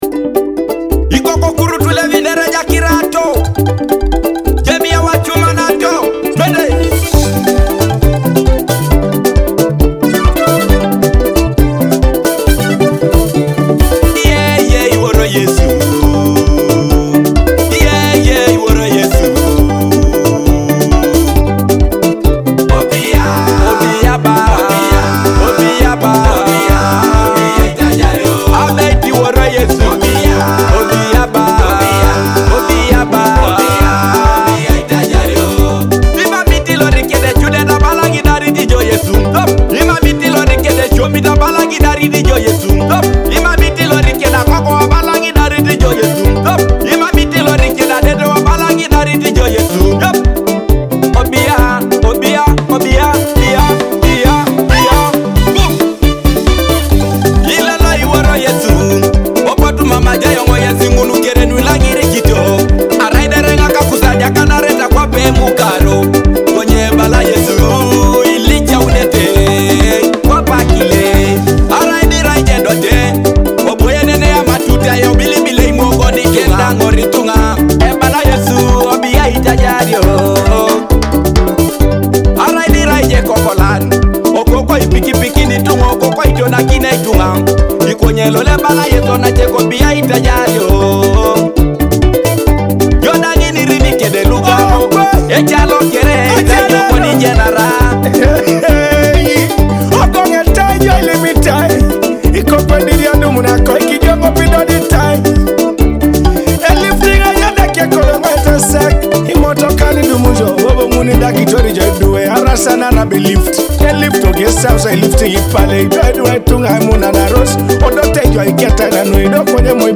a powerful Teso gospel song